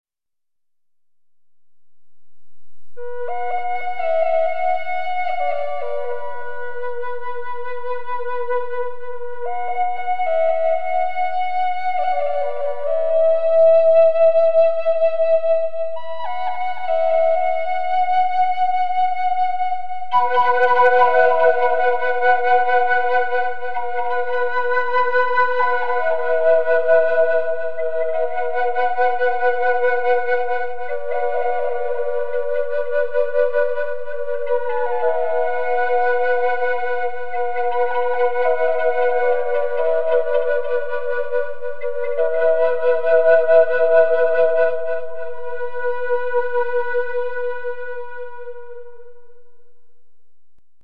Instrument Grade Triple Barrel Poplar Flute is keyed to Bm - my dished finger holes - so comfortable this flute will become an extension of yourself! Approx 20" in length, Very Loud, Crystal Clear voice is so AWESOME you will surely Love the very moment your breath enters the flute's spirit!
Can be played as a single flute, double, or triple!!!!!!!!
Well, as the flute goes into song, for the first 20 seconds or so, I play only the single center barrel.....
As I take the flute to the next level by playing two barrels, they are in disbelief, if I say so myself.